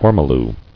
[or·mo·lu]